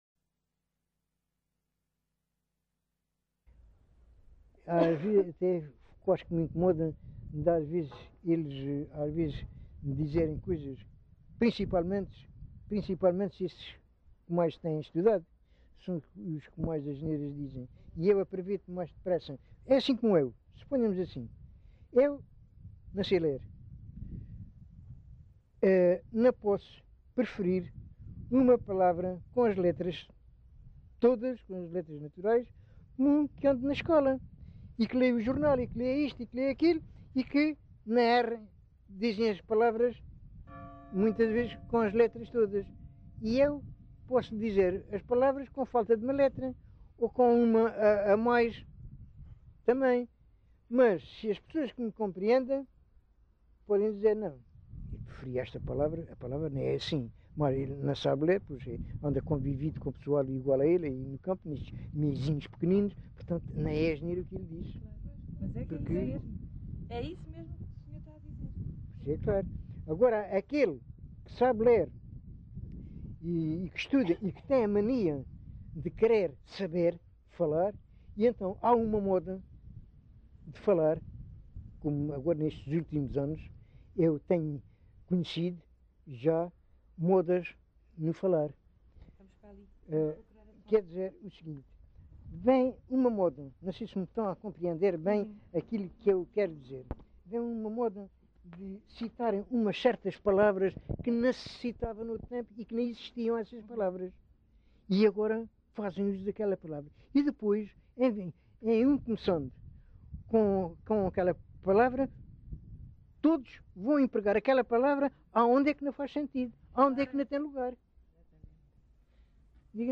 LocalidadePorches (Lagoa, Faro)